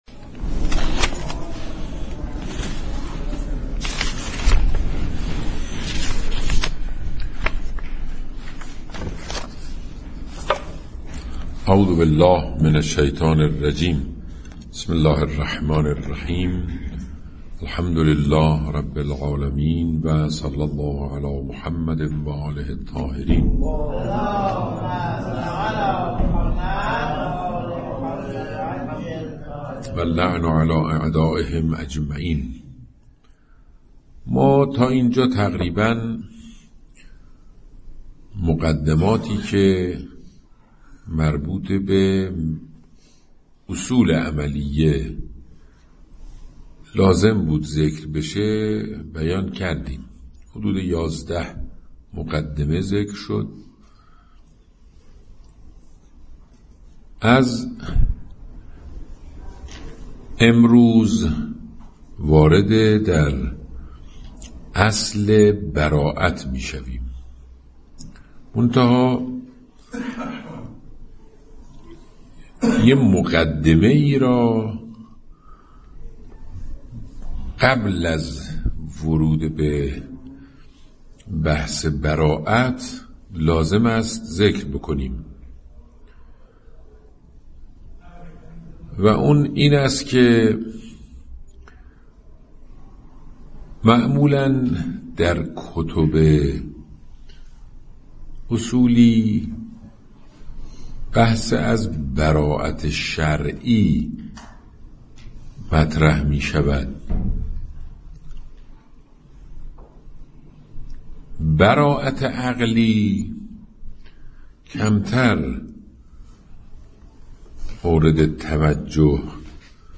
خارج اصول؛ جلسه سی و هفتم؛ اصل برائت؛ برائت عقلی؛ مطلب اول؛ پیشینه برائت عقلی